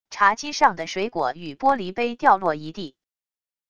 茶几上的水果与玻璃杯掉落一地wav音频